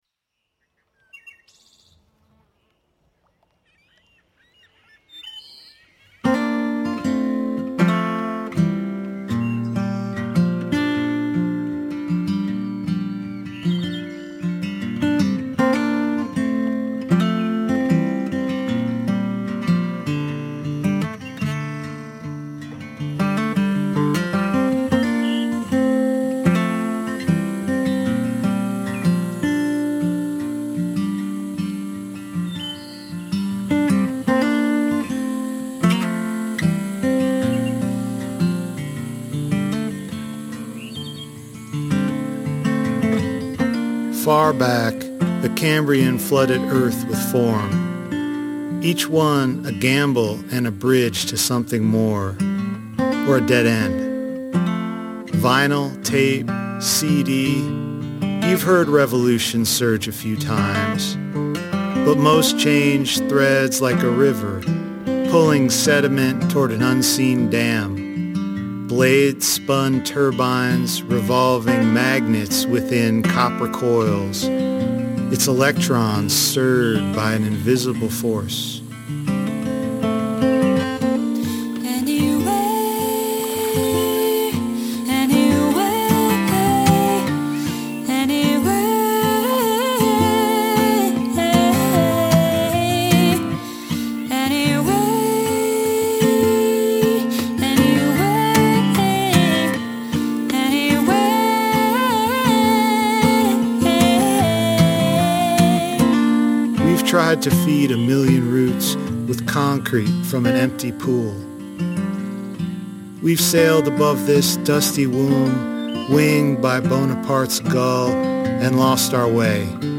“Anyway” is a collaborative poem-song born from shared reverence for the Great Salt Lake and the tangled systems that sustain it.
Musically, this piece weaves together guitar, ambient sound, and lyrical repetition to capture the convergence of ecology and the human voice. The lyrics draw on ancient origins and contemporary uncertainties, moving across eras and elements to reflect the shifting material and emotional terrain we inhabit. The refrain “anyway” becomes a kind of mantra—one part lament, one part incantation.